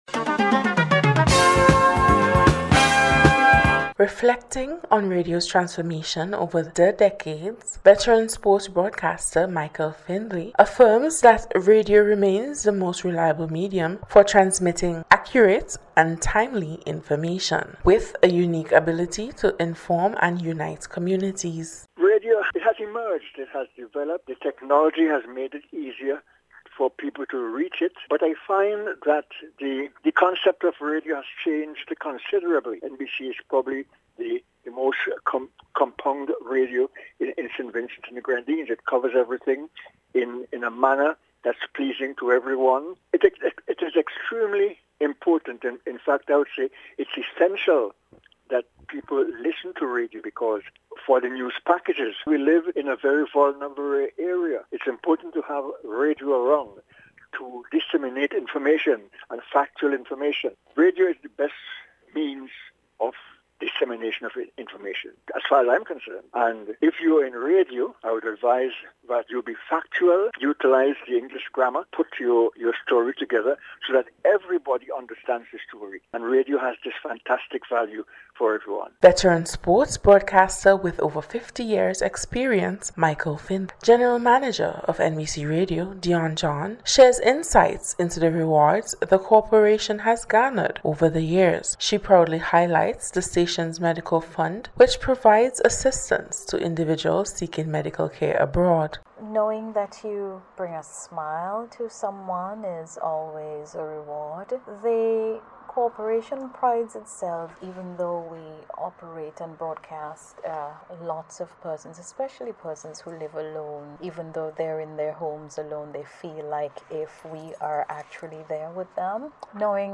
NBC’s Special Report- Thursday 13th Februart,2025
WORLD-RADIO-DAY-REPORT.mp3